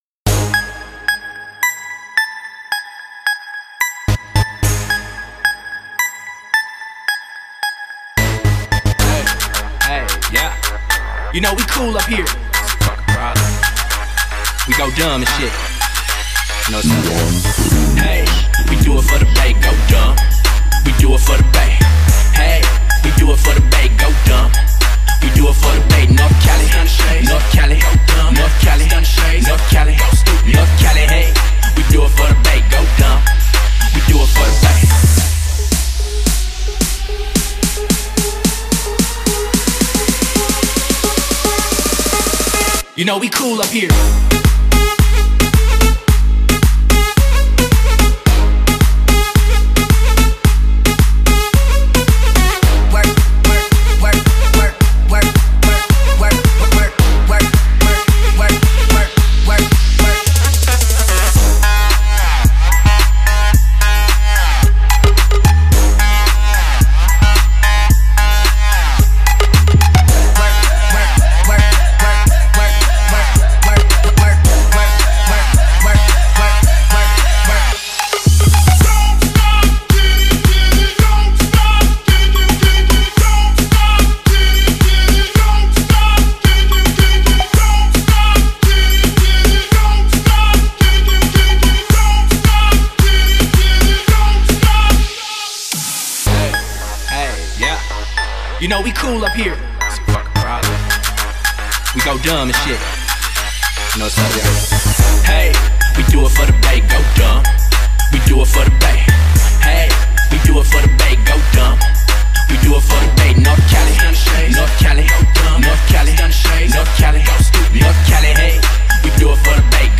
Download the bass remix for the meeting